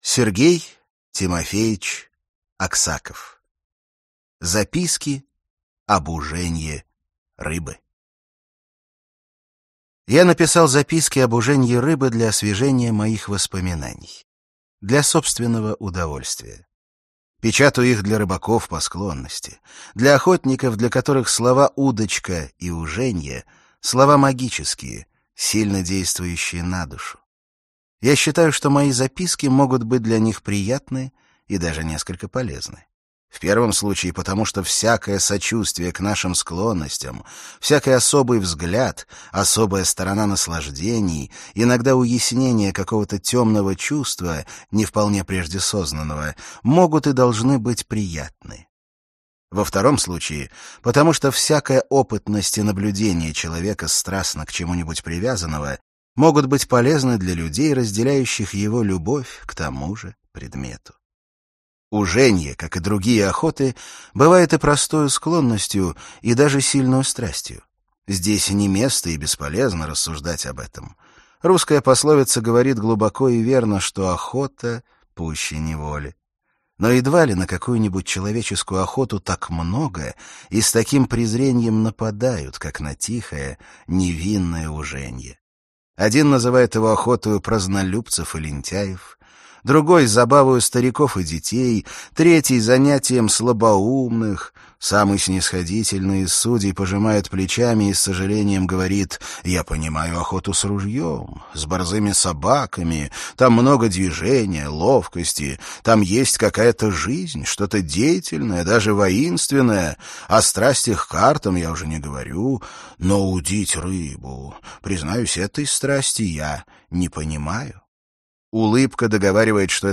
Аудиокнига Записки об уженье рыбы | Библиотека аудиокниг
Прослушать и бесплатно скачать фрагмент аудиокниги